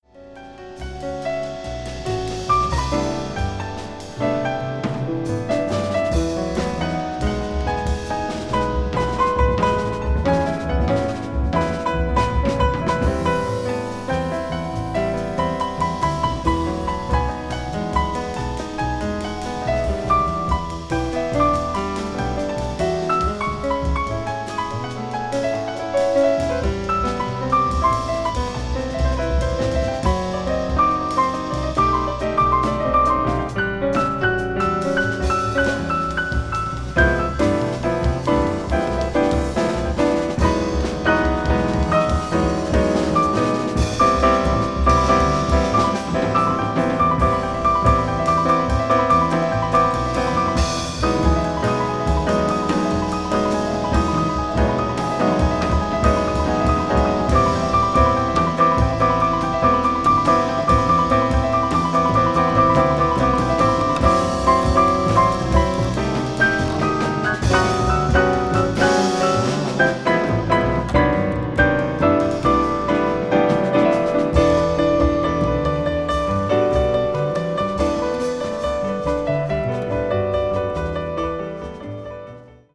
contemporary jazz   jazz vocal   spiritual jazz